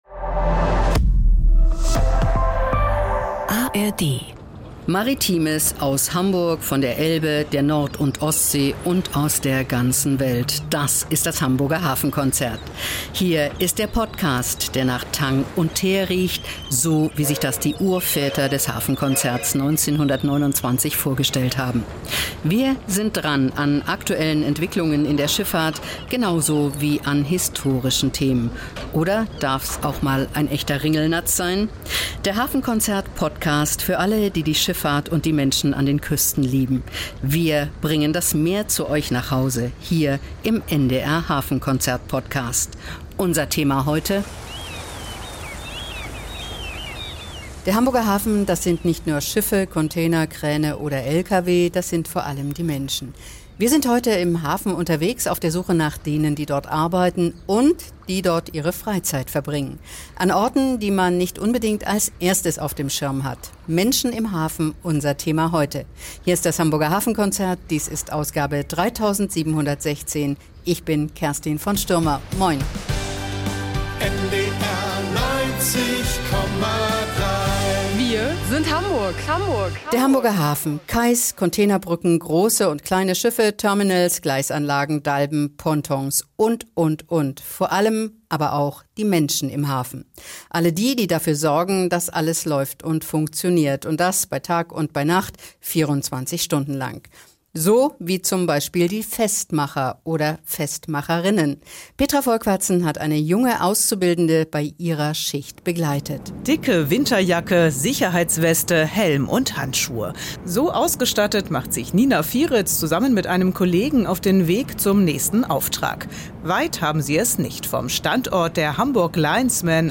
Der Hamburger Hafen – das sind nicht nur Schiffe, Container und Kräne, sondern vor allem Menschen. In dieser Ausgabe des Hafenkonzert-Podcasts sind wir im Hafen unterwegs und treffen diejenigen, die hier arbeiten oder ihre Freizeit verbringen.